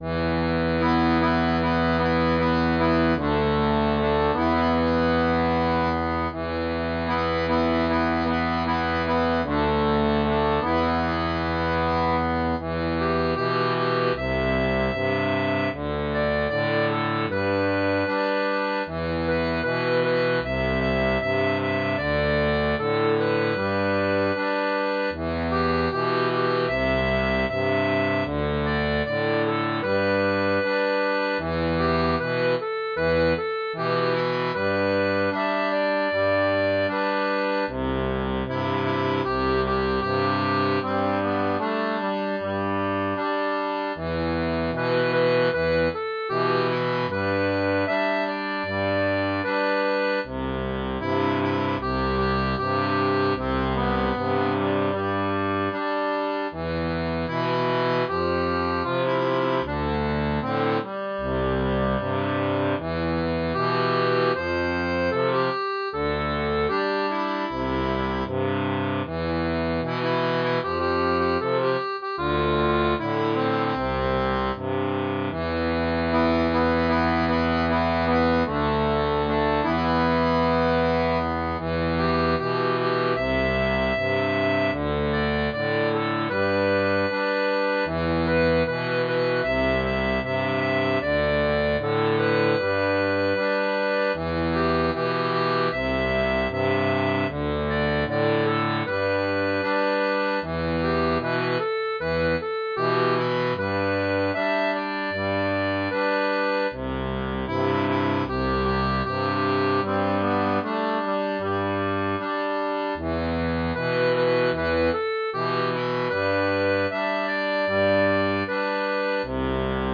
• Un fichier audio basé sur la rythmique originale
Pop-Rock